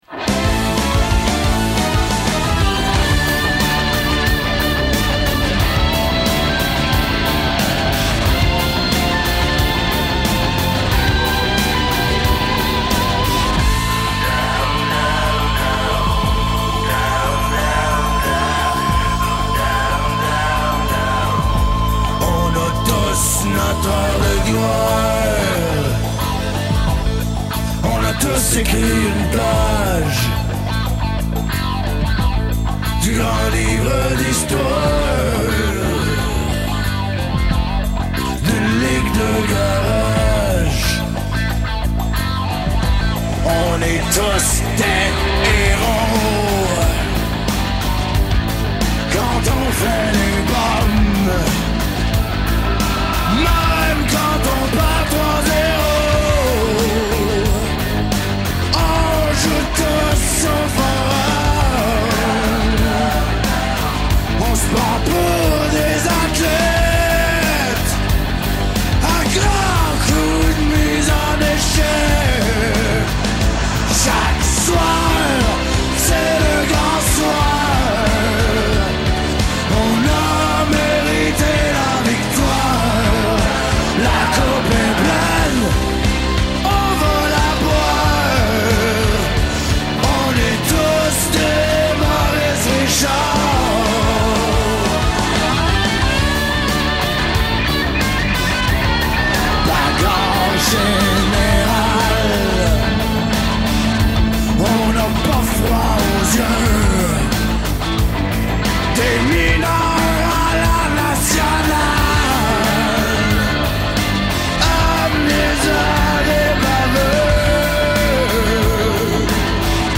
le rockeur québécois